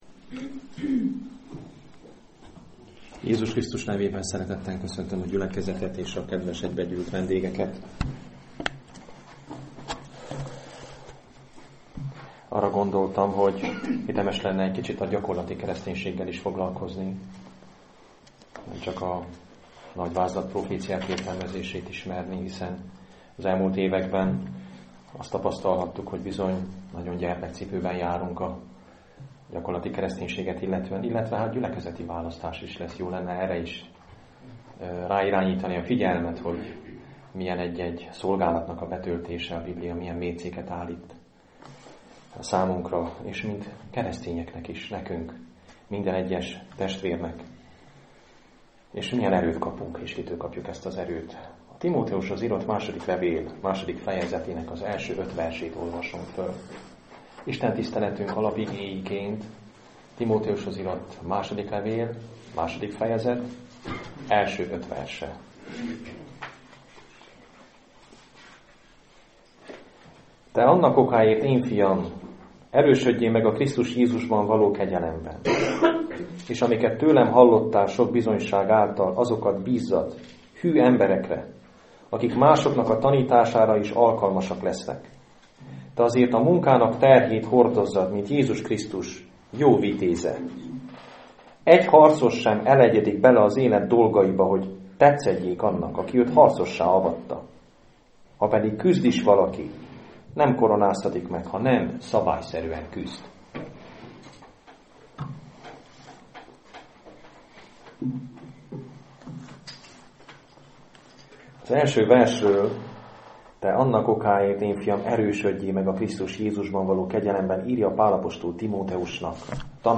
2 Timóteus 2,1-5 Igehirdetések mp3 Link az igehirdetéshez Hasonló bejegyzések Igehirdetések mp3 Bűnrendezésről.